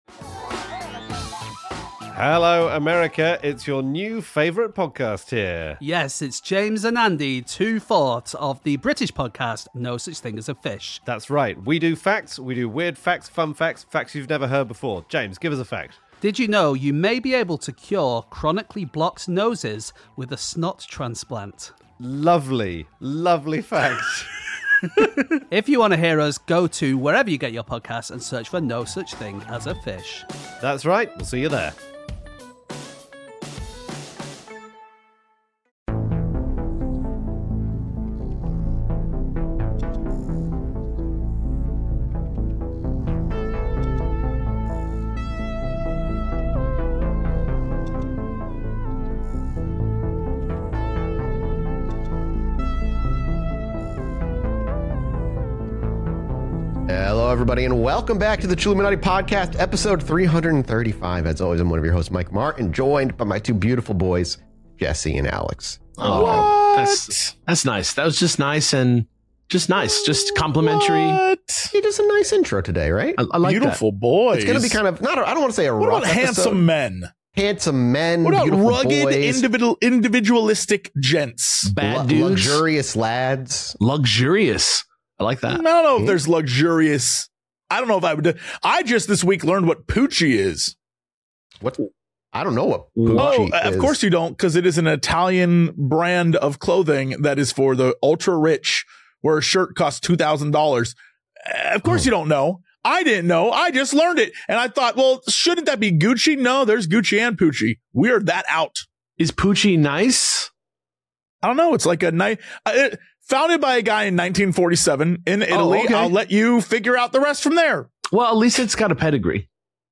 CHILLUMINATI is a weekly comedy podcast